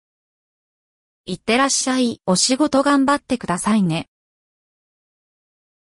Alexaで用意されている標準の読み上げ機能は、以前と比べて十分発音や発話がうまくなってきてはいるものの、やはり機械による読み上げ感が否めないシーンもあります。
やはりどこか機械が読んでいる印象を受けます。
yomiage.mp3